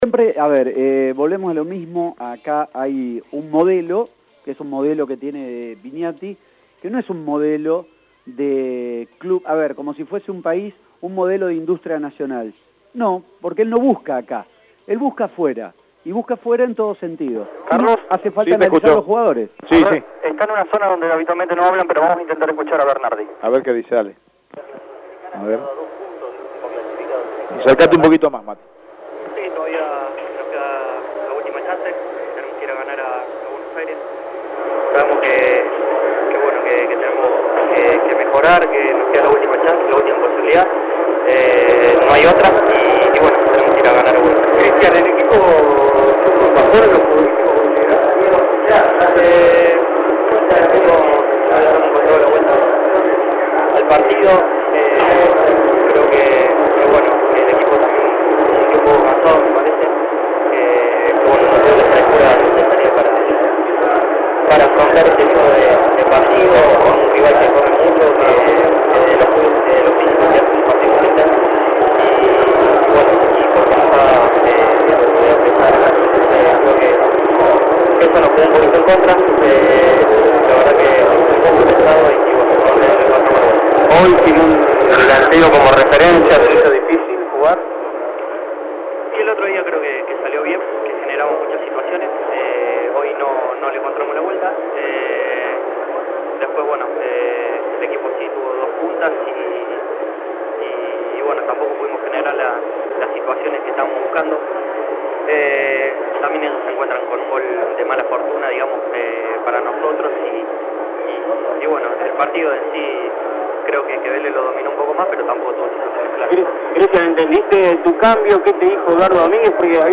El que sí lo hizo fue Christian Bernardi y dejó estas declaraciones: